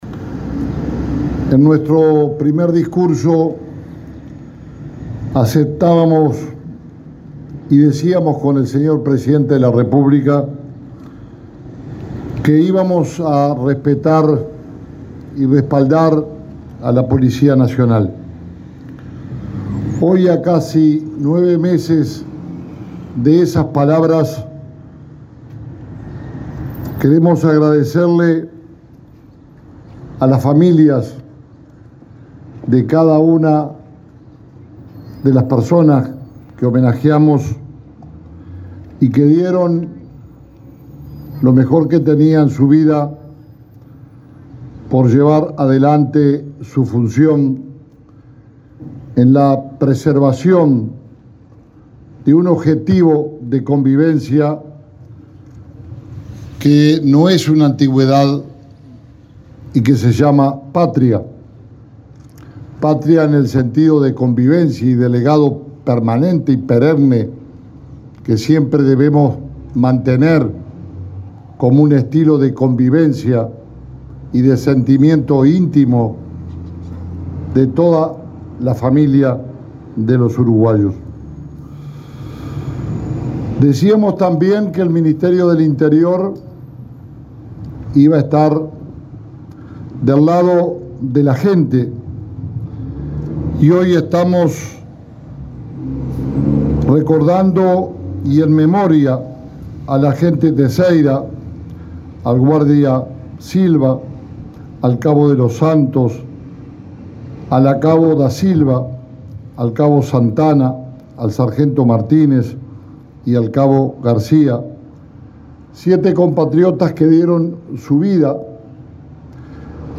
Palabras del ministro del Interior, Jorge Larrañaga, en la conmemoración del Día del Policía Caído en Cumplimiento del Deber